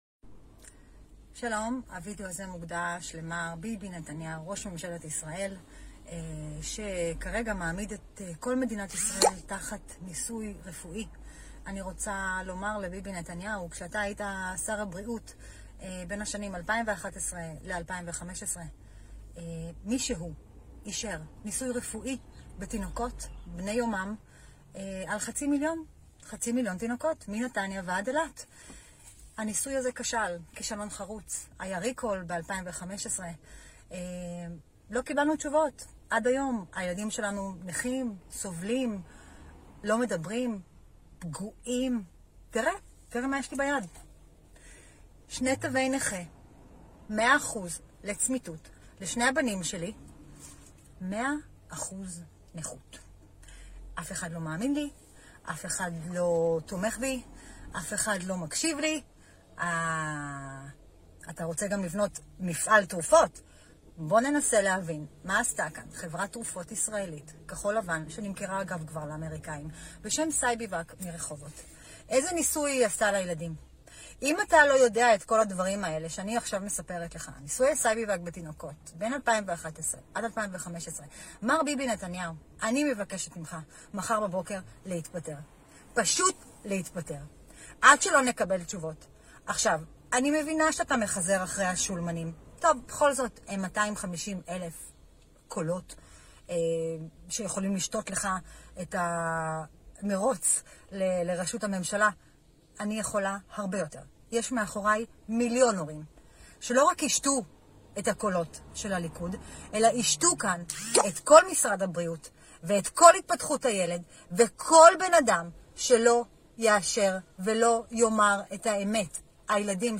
הדוברת